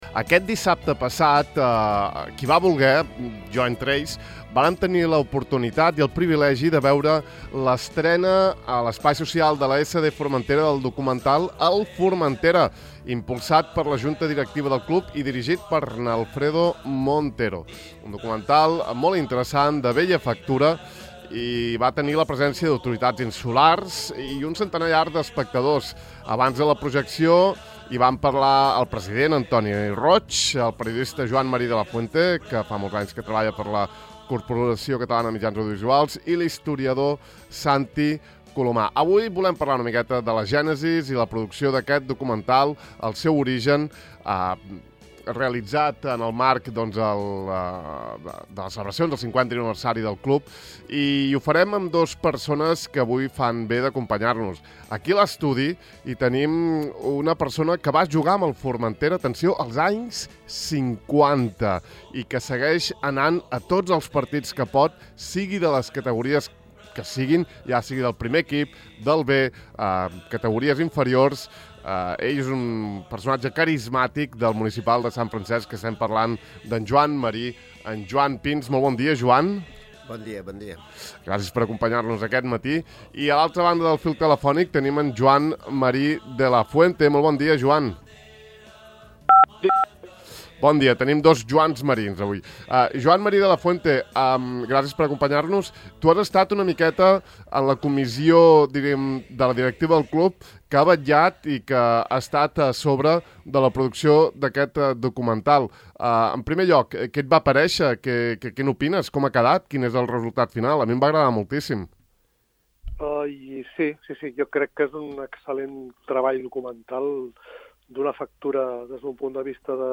Avui n’hem volgut parlar amb el periodista